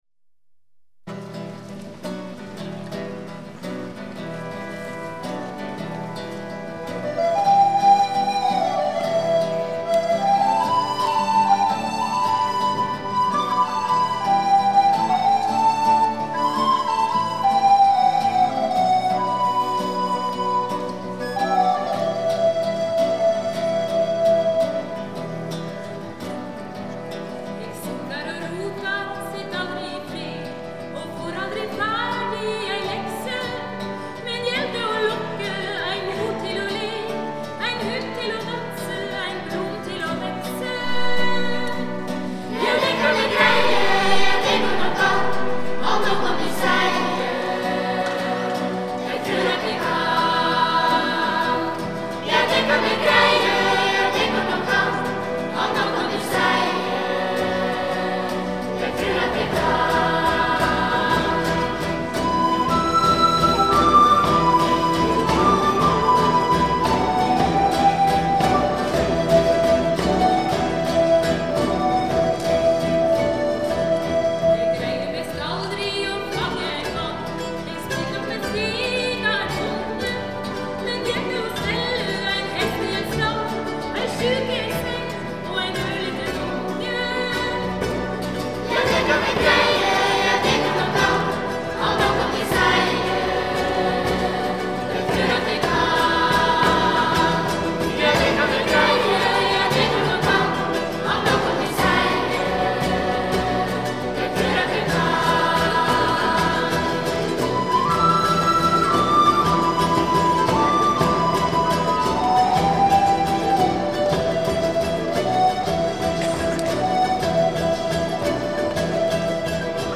Inti, Blokkfløytistene Uranienborg og St.Sunniva, Steinerskolens jentekor,
Tone Hulbækmo og Hans Fredrik Jacobsen
Fra konserten "Fra Andes til Dovre" i Oslo Domkirke, Oslo 6. november 1998.